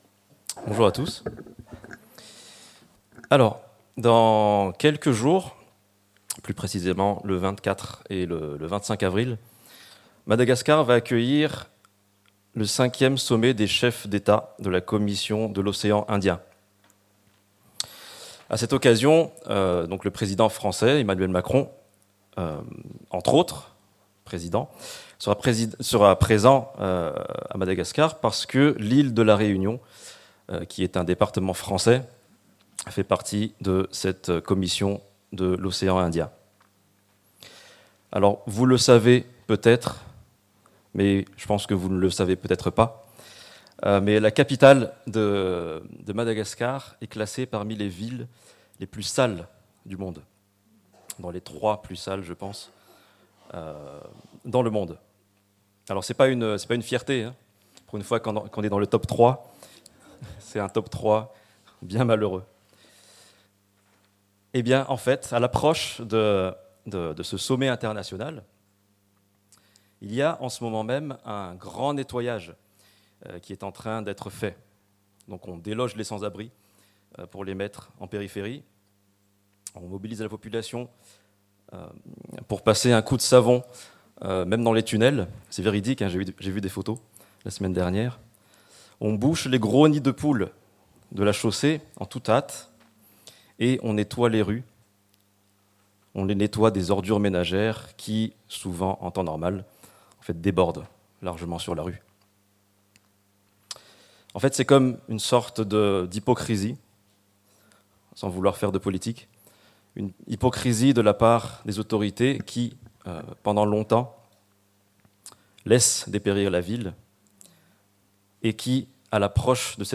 Marc Prédication textuelle